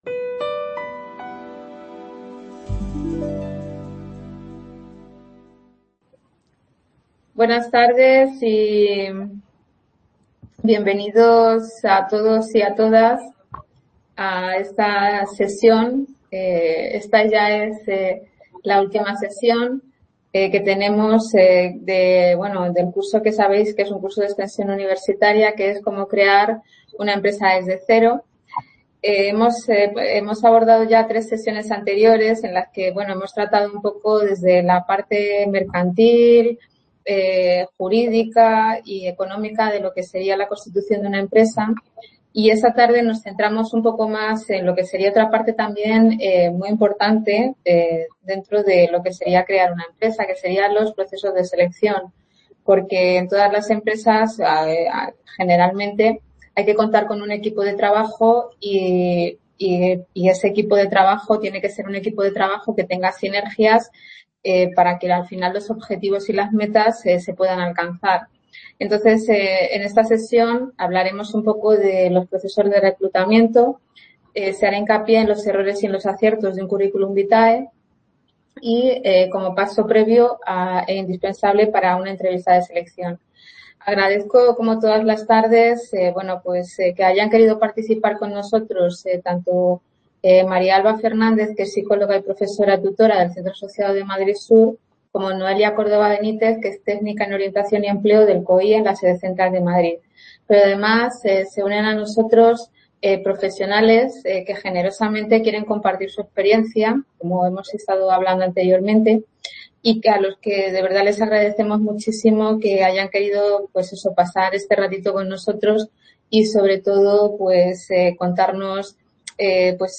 Procesos de selección - 10 Errores y 10 Aciertos en tu currículo (varios ponentes)